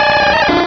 Cri de Carapuce dans Pokémon Rubis et Saphir.